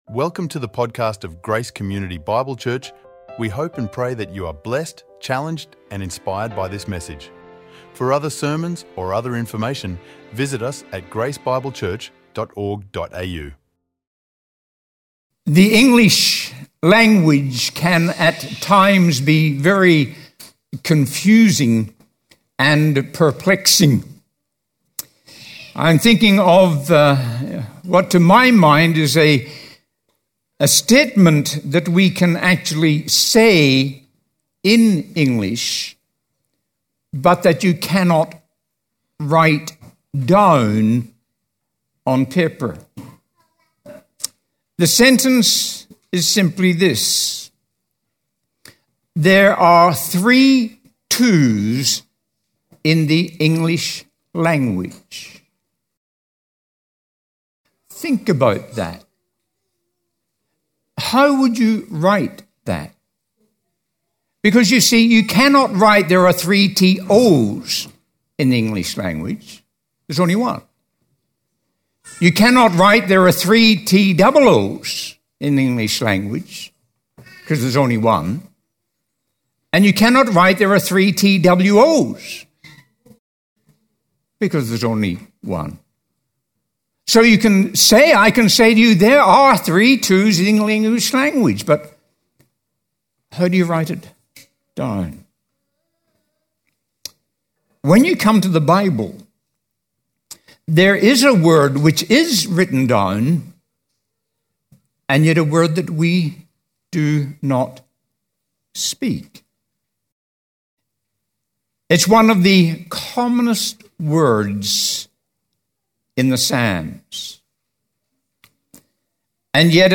recorded live at Grace Community Bible Church, on the topic “What’s In A Word?” – from the Psalm 3.